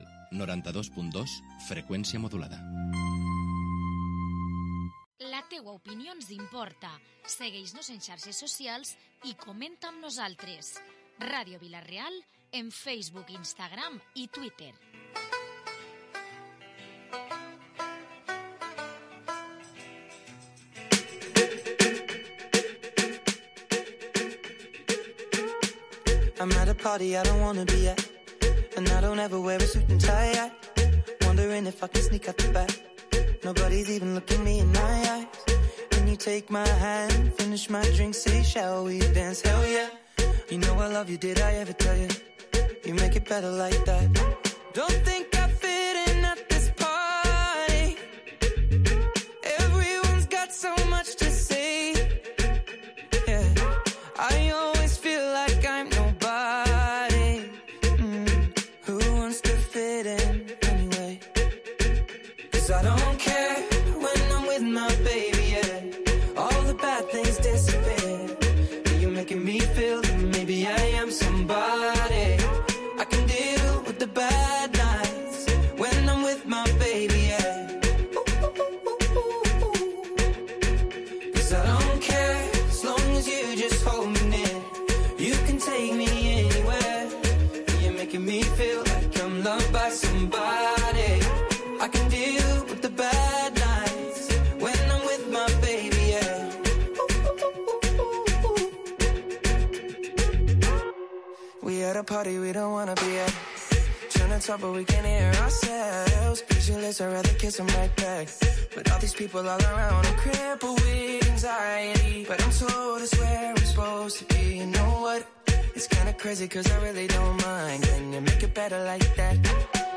Hoy en Protagonistes hemos repasado los actos previstos en Vila-real por motivo del 25N, hemos conocido los cortos que se proyectan esta noche en la sesión provincial de Cineculpable y hemos tenido tertulia política con representantes del PSPV, PP, Compromís, Ciudadanos, Vox y Unides Podem.